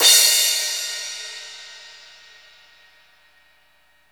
CYM XCRASH1H.wav